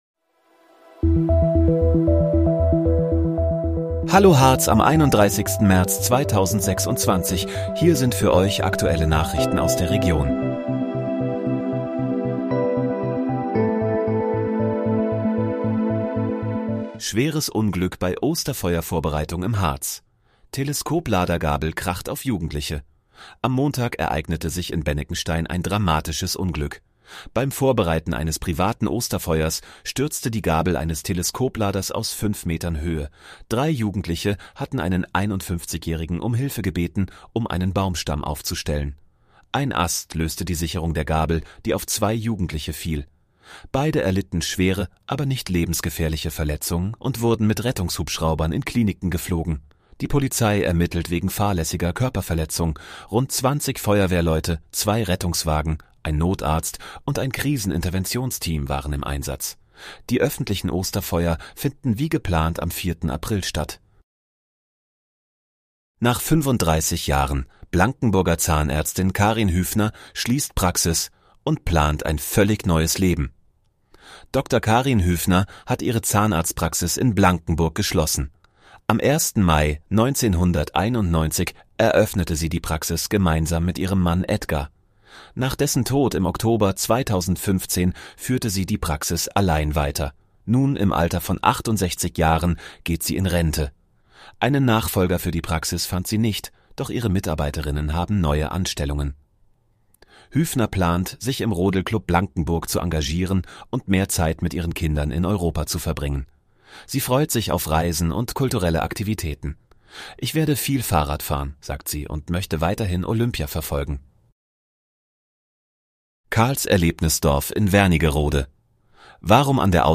Hallo, Harz: Aktuelle Nachrichten vom 31.03.2026, erstellt mit KI-Unterstützung